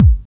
07.2 KICK.wav